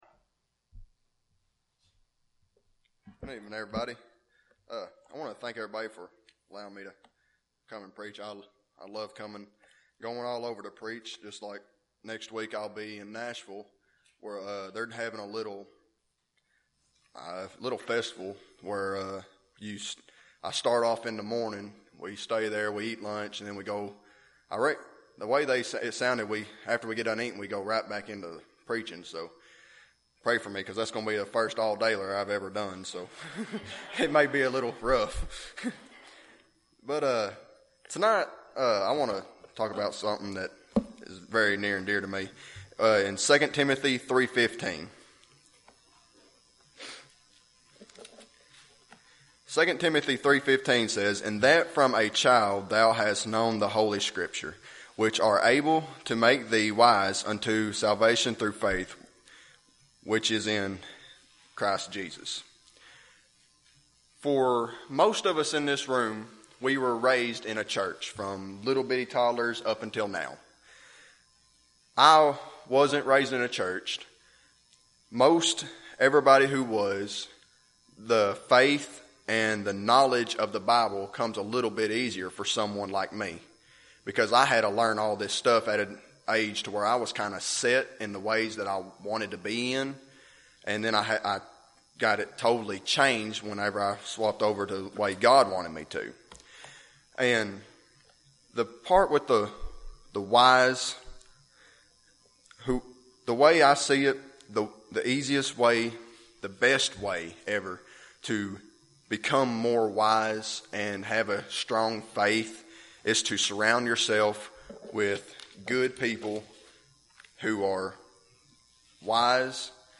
Evening Message